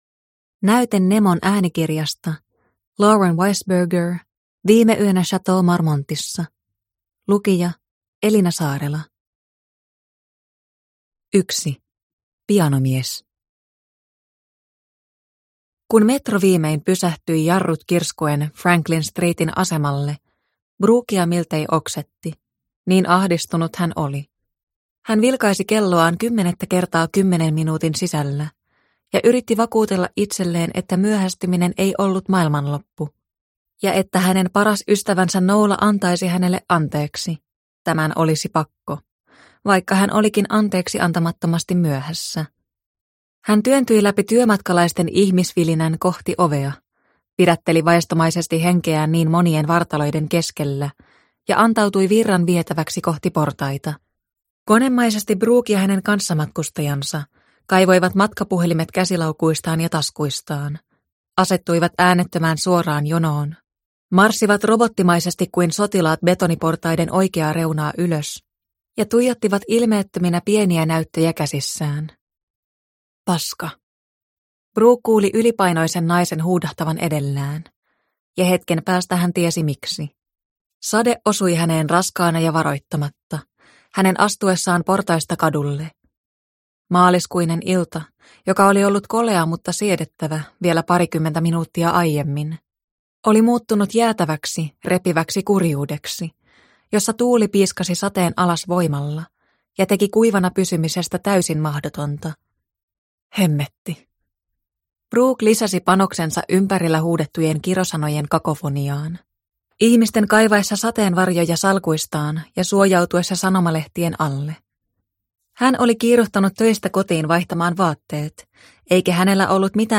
Viime yönä Chateau Marmontissa – Ljudbok – Laddas ner